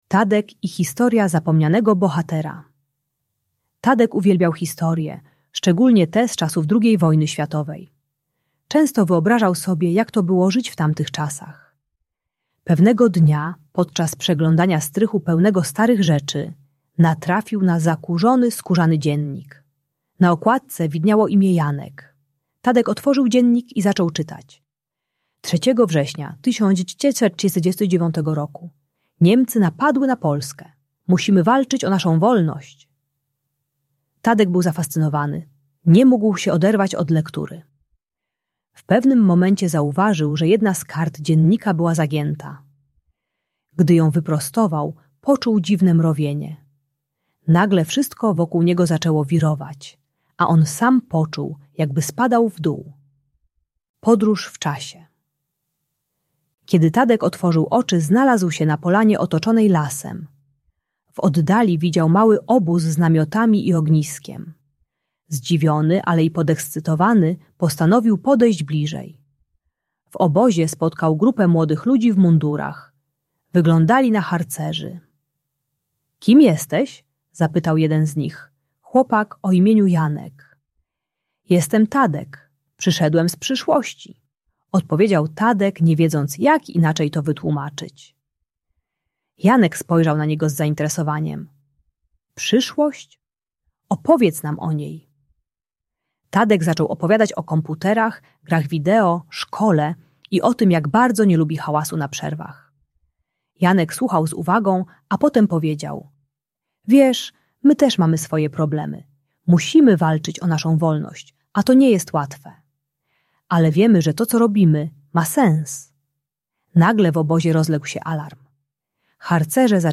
Tadek i Historia Zapomnianego Bohatera - Szkoła | Audiobajka